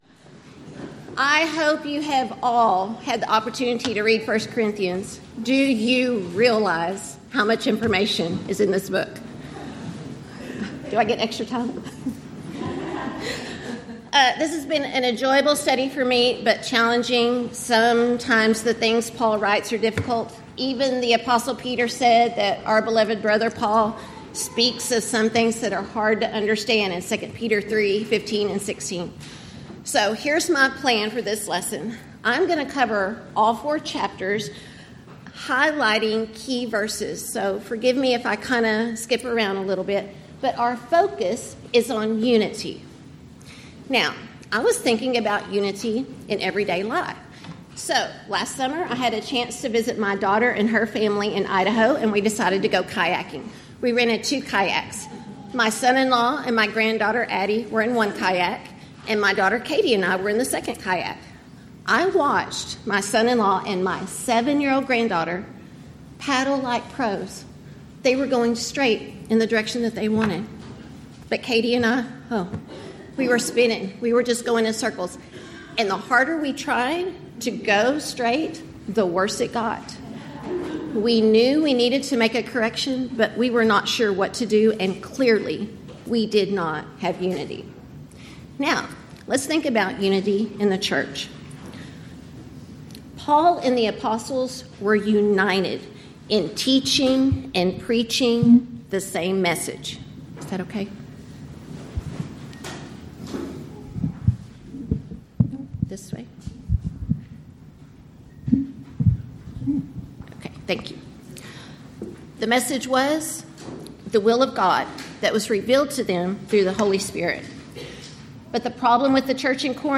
Event: 13th Annual Texas Ladies in Christ Retreat
Ladies Sessions